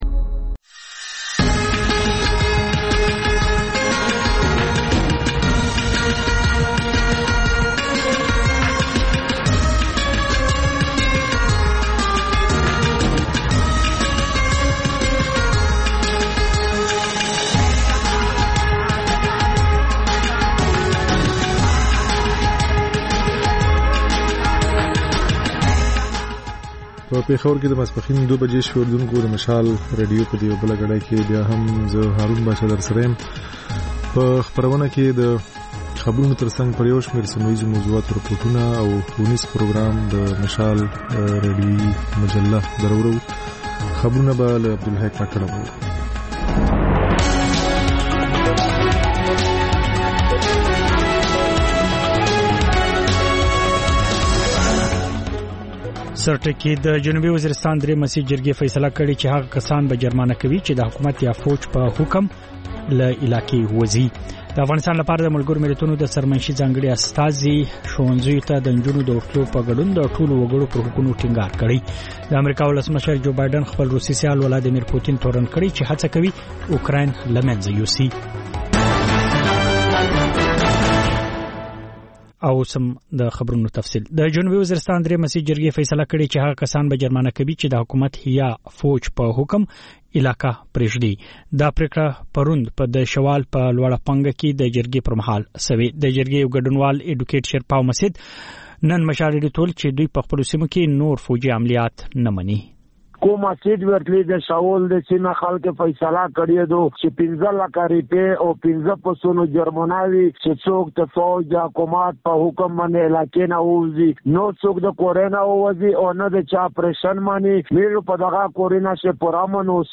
د مشال راډیو دویمه ماسپښینۍ خپرونه. په دې خپرونه کې تر خبرونو وروسته بېلا بېل رپورټونه، شننې، مرکې خپرېږي.